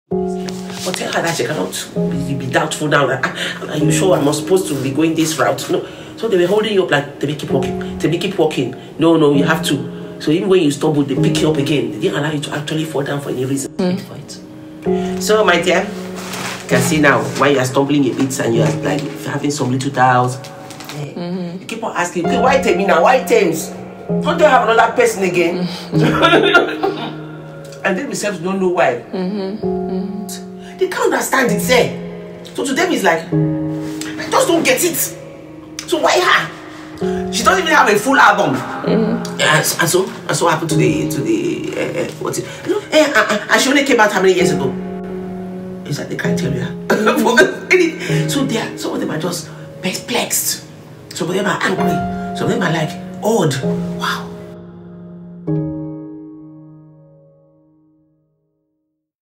AfroBeats | AfroBeats songs
soulful vibes